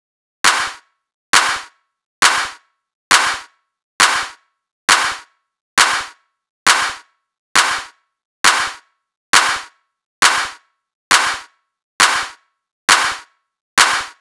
TI CK7 135  Clap 2.wav